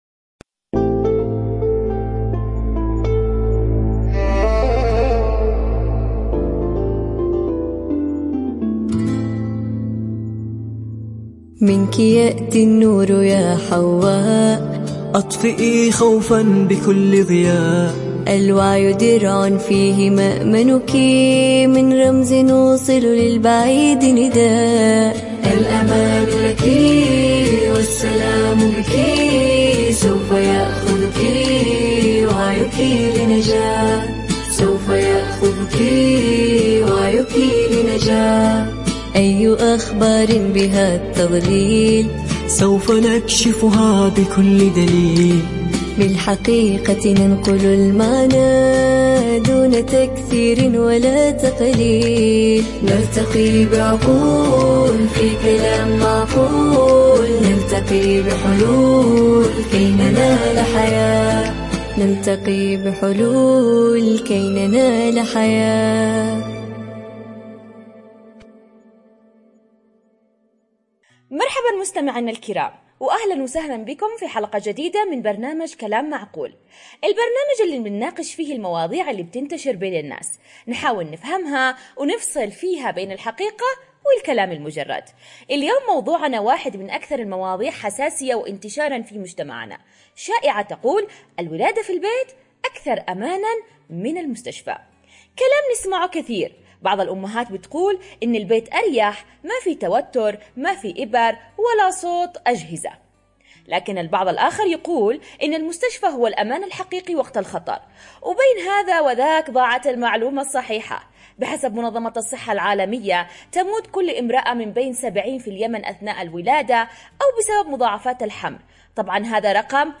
نقاشٌ